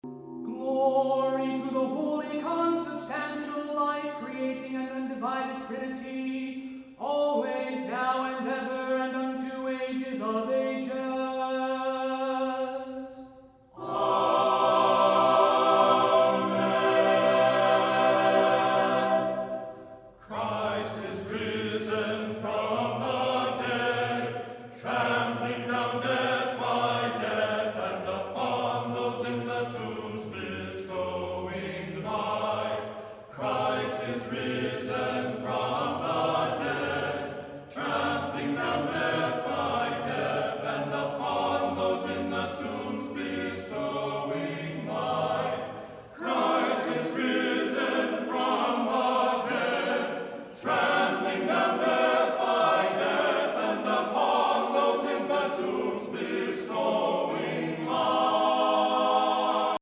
vocal ensemble
Orthodox liturgical repertoire
Znamenny Chant